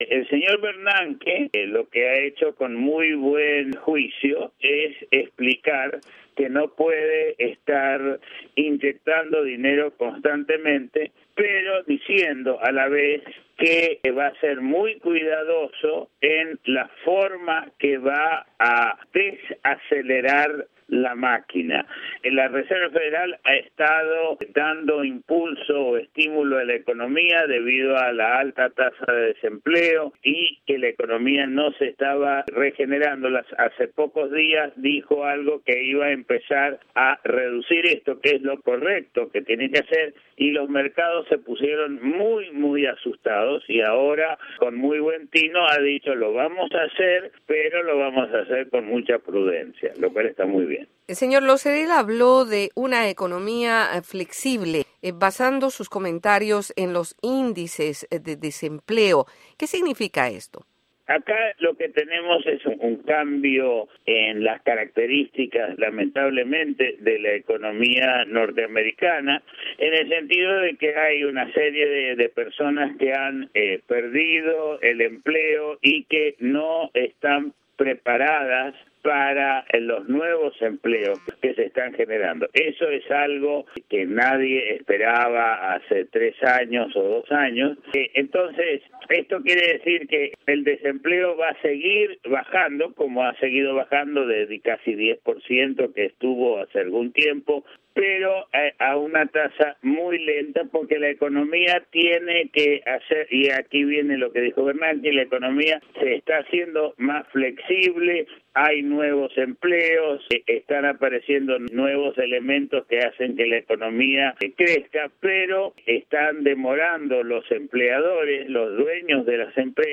Entrevista de Economía